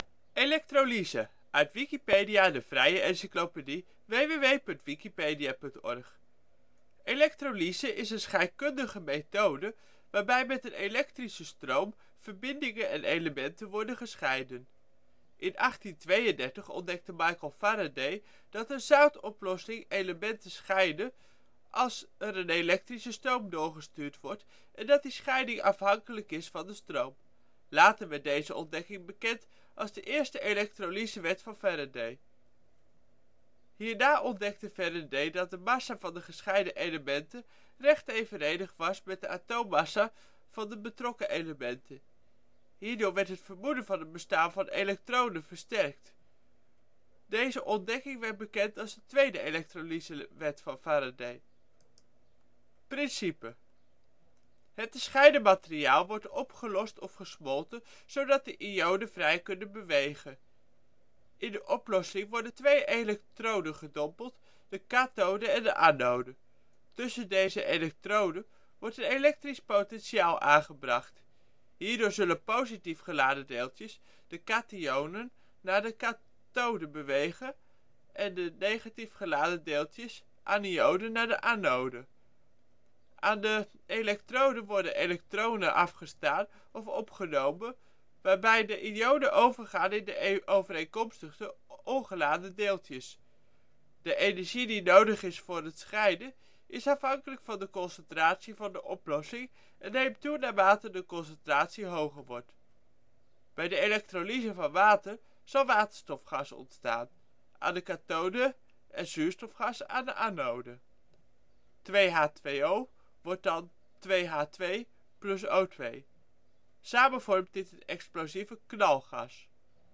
Zelf ingesproken.
Nl-Elektrolyse-article.ogg.mp3